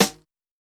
• 2000s Sizzle Snare Drum Sound G# Key 79.wav
Royality free snare sound tuned to the G# note.